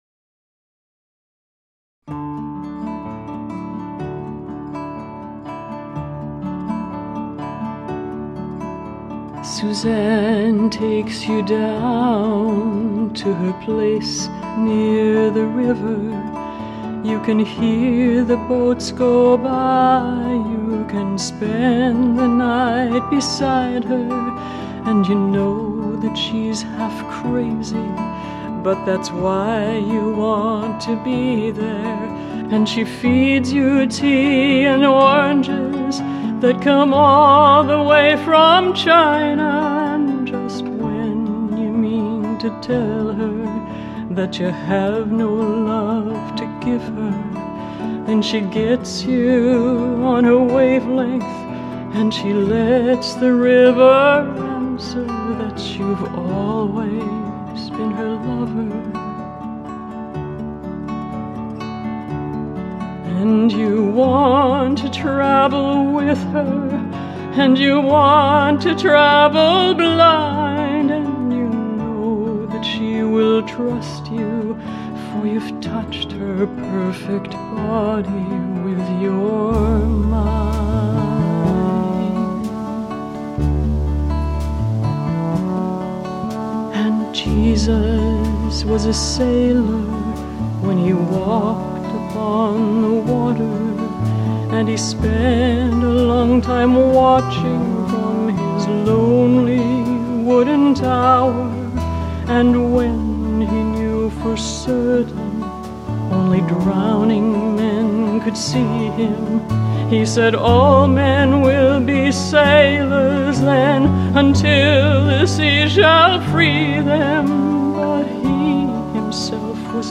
viola
cello
bass
percussion
guitars